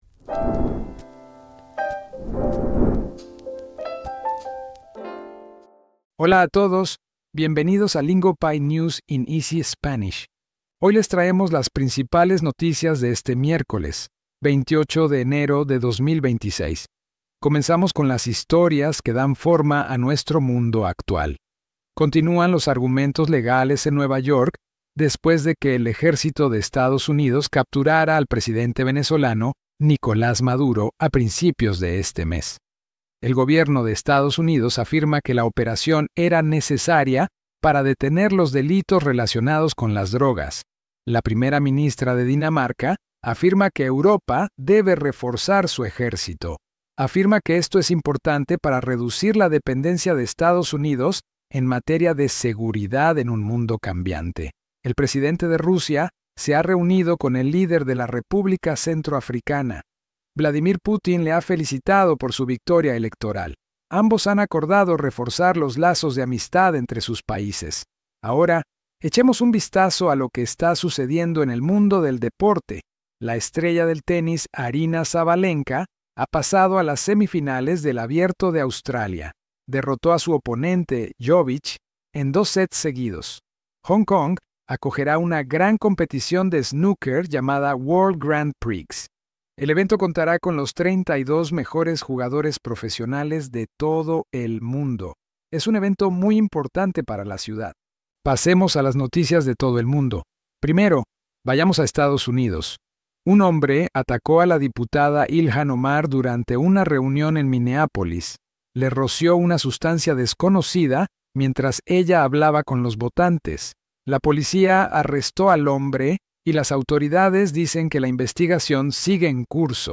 Lingopie’s News in Easy Spanish is a slow, learner-friendly Spanish podcast that helps you actually understand the news. We deliver today’s biggest headlines in clear, steady Spanish so you can build real listening confidence, expand your vocabulary in context, and train your ear for how Spanish is spoken.